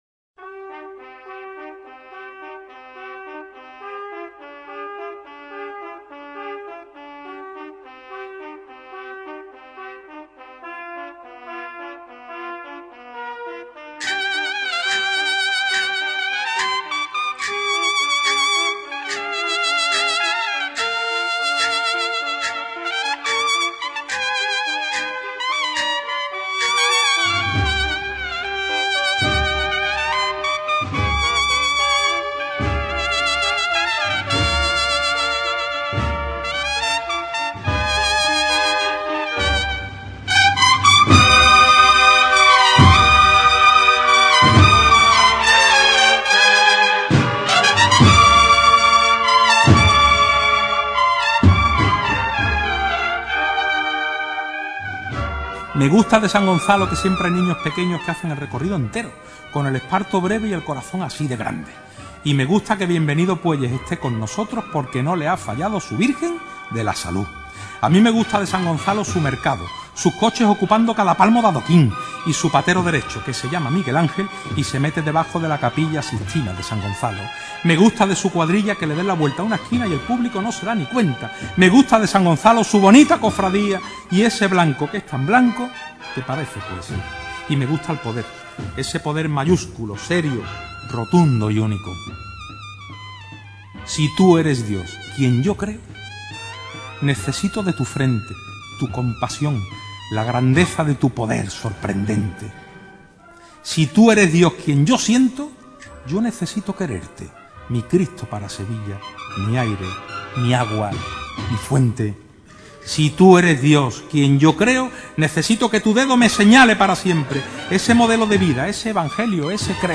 Guitarra
Temática: Cofrade